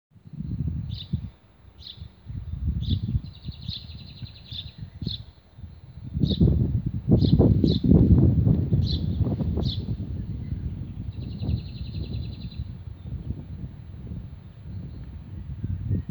домовой воробей, Passer domesticus
Ziņotāja saglabāts vietas nosaukumsDārzs
СтатусПоёт